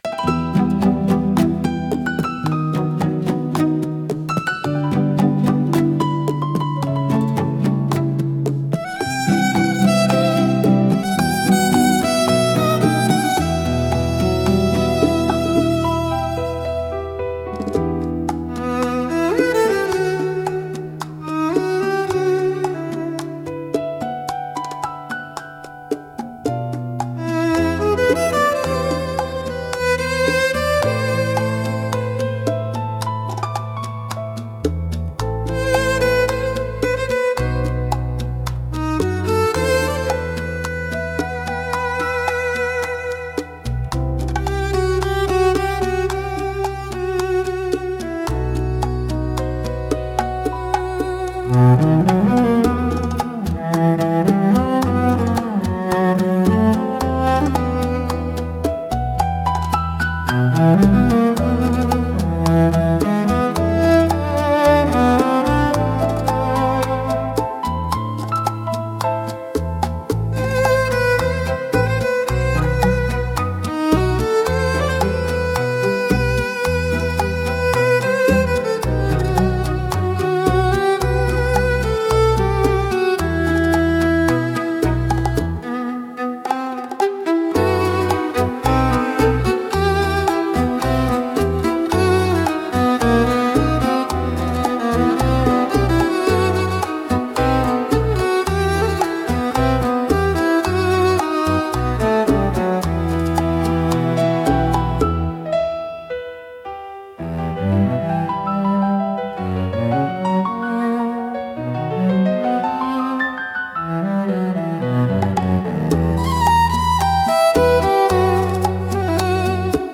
música e arranjo IA) instrumental